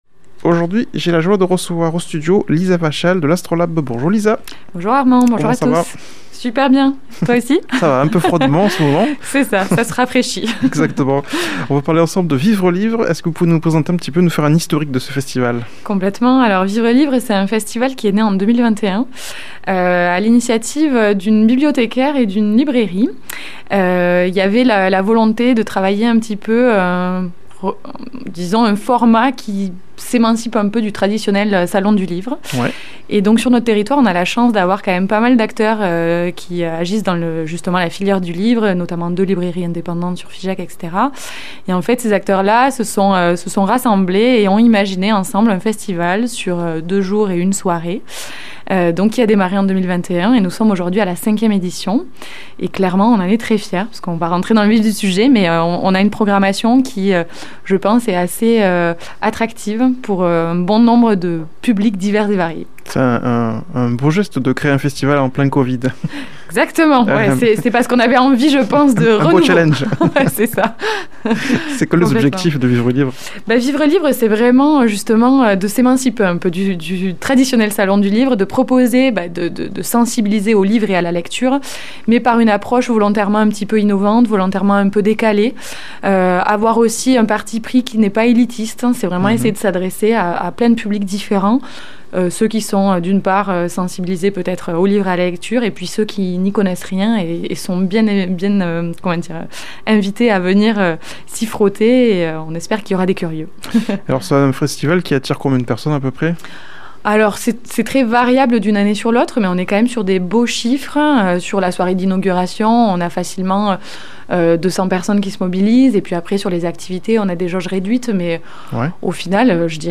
Elle vient évoquer l'édition 2026 du festival Vivre Livre qui aura lieu dans plusieurs lieux de Figeac du 2 au 4 Avril